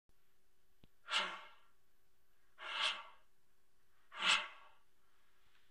Bull Elk Sounds The Bull Elk make the most magnificent and powerful sounds.
Heavy Breathing
heavy_breathing.wma